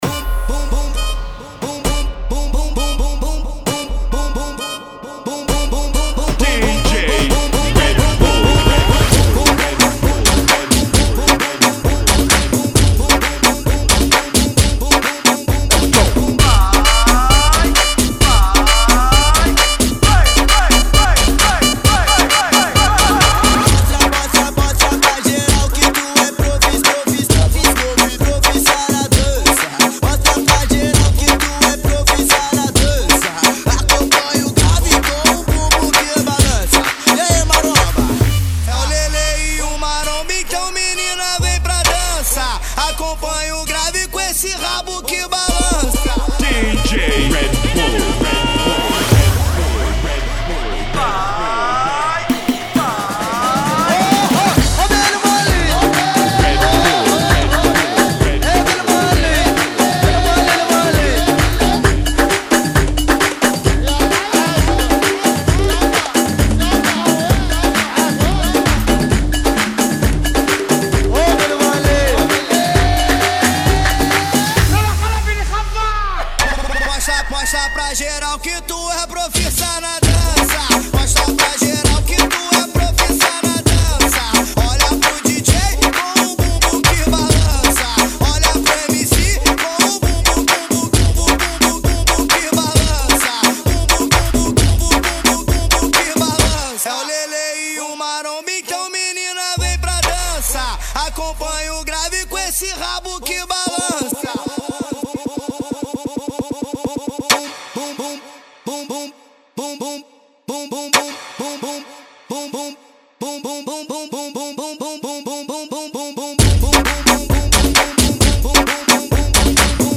132 bpm